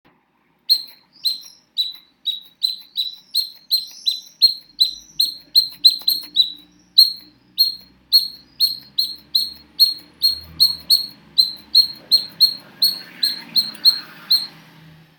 cliccate qui per l’ascolto] che, per essere un richiamo, è un richiamo e infatti richiama, ma che serva a richiamare anche i morti, oltre che le allodole, non so, non ci giurerei...
Allodole.mp3